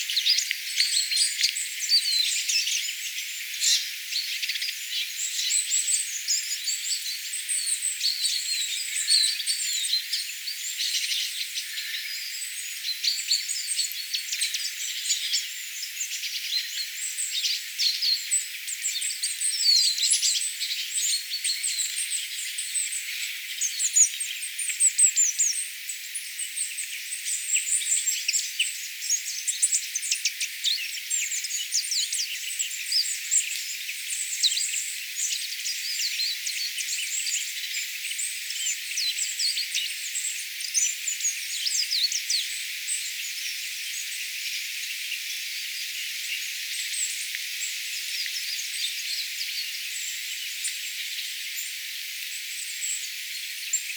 innokasta pikkulintuparven ääntelyä
Oli niin paljon erilaisia pikkulintuja parvessa.
innokasta_pikkulintuparven_aantelya.mp3